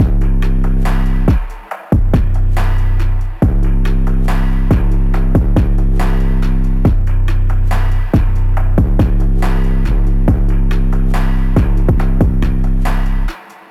• Dirty Phonk Saturated Drum Loop F#M/Gm 158.ogg
Hard punchy kick sample for Memphis Phonk/ Hip Hop and Trap like sound.